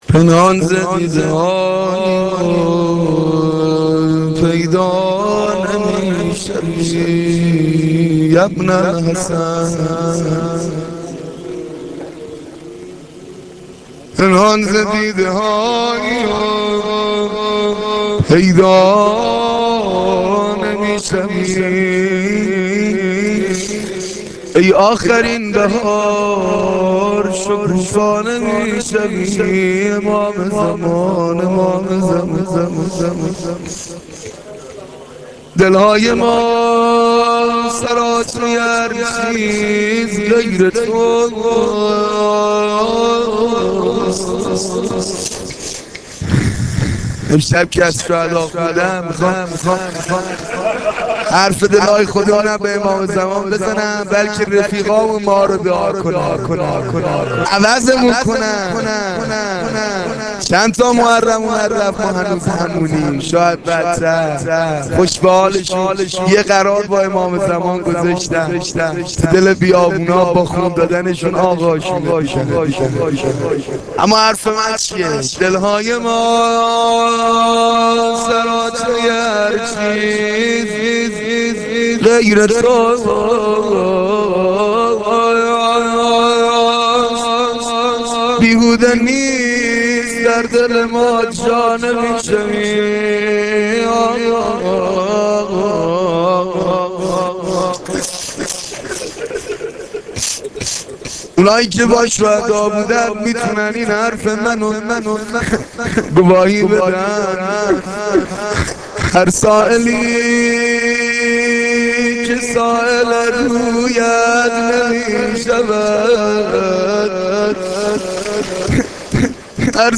مداحی روضه شب پنجم